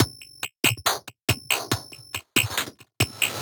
Gamer World Drum Loop 2.wav